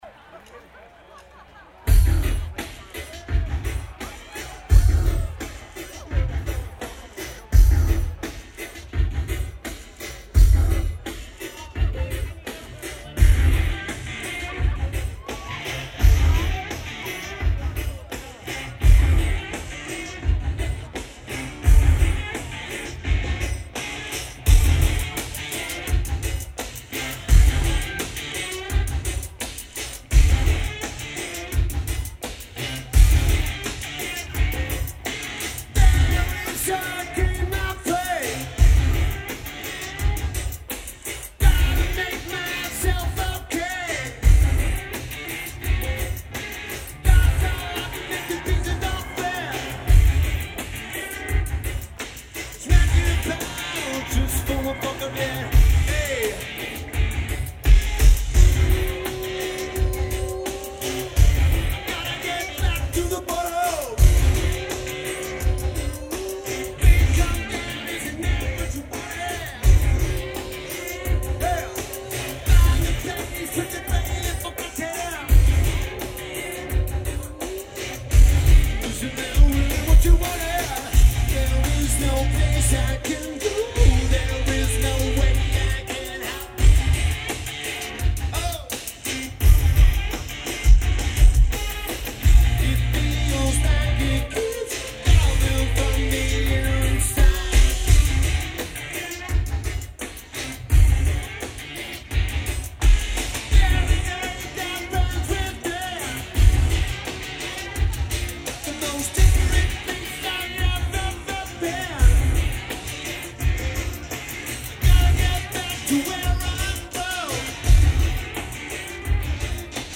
McMenamins Edgefield